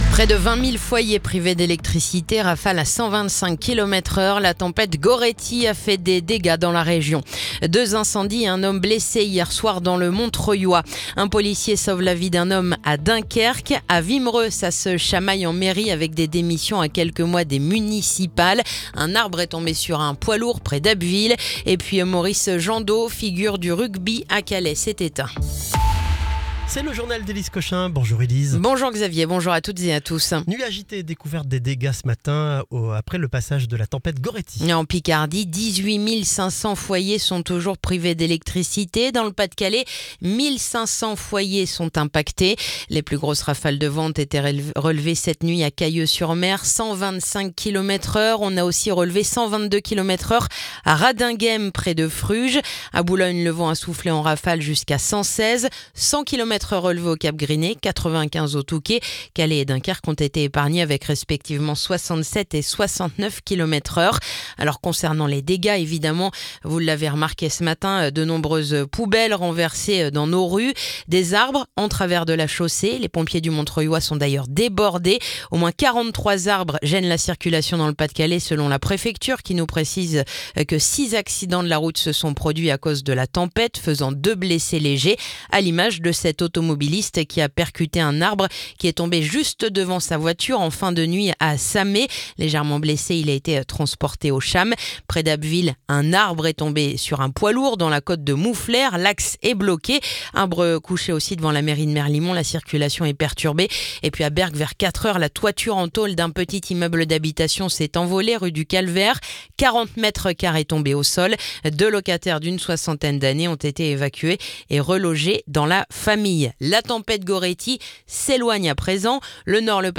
Le journal du vendredi 9 janvier